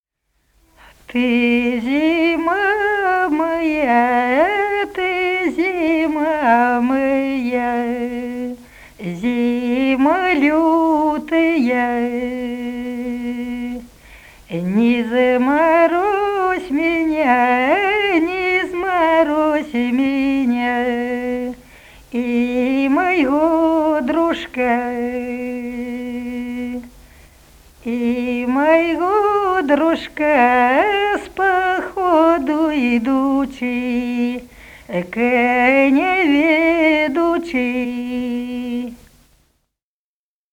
Народные песни Смоленской области
«Ты, зима моя, зима лютая» (вечерочная мужская).